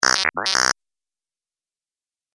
جلوه های صوتی
دانلود صدای ربات 35 از ساعد نیوز با لینک مستقیم و کیفیت بالا